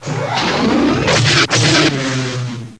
corelocklaser.ogg